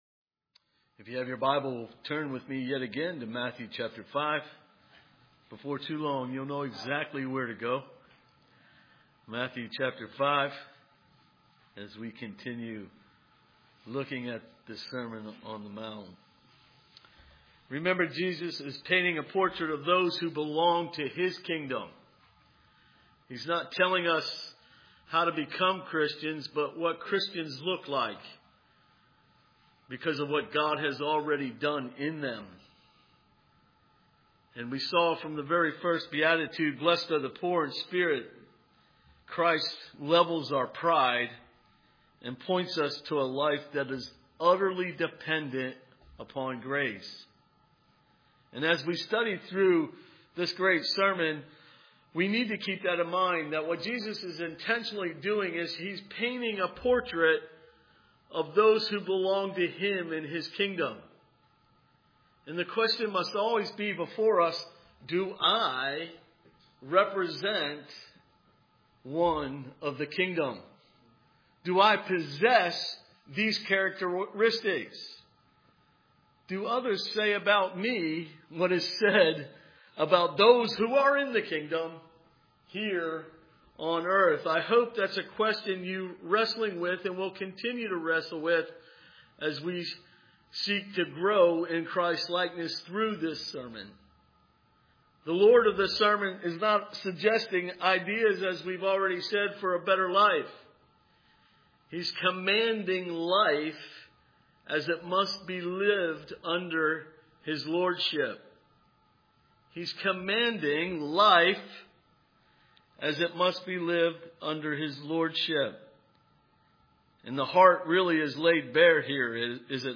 Matthew 5:1-12 Service Type: Sunday Evening Matthew 5:1-12 What does it mean to be blessed?